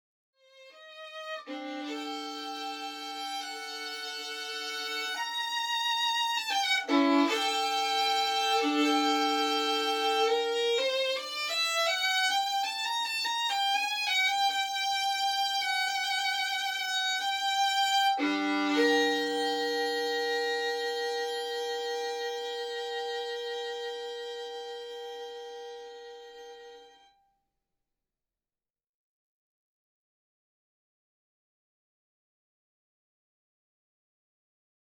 ドライソースと解析で得たバイノーラルのインパルスレスポンスを畳み込むことで様々な空間や、同じ空間であっても異なる場所での聴こえ方の違いが体験できます。
ここでご紹介する音源はすべて両耳用のヘッドホンでお聴きいただくことを前提に作成していますので、ヘッドホンを装着してご試聴いただくことをお勧めします。
まずは、無伴奏ソロヴァイオリンの音楽をお聞きください。聞いている場所は、センターと前方左側です。
またステージ向かって左側前方座席に座りますと、そこからはステージが右側に位置しますので、右側から音が聞こえていることがはっきりとおわかりいただけるのではないでしょうか？